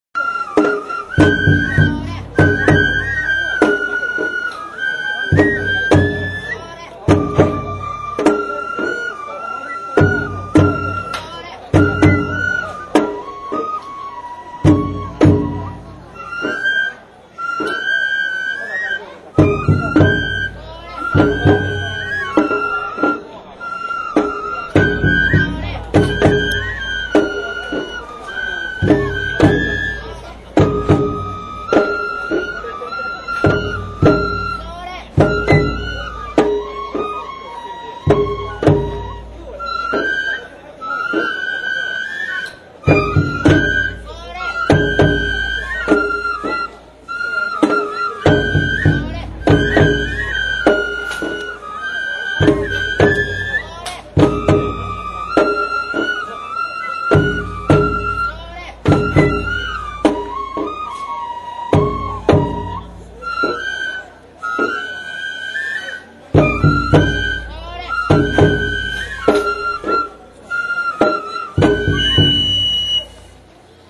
尾張の山車囃子～尾張・三河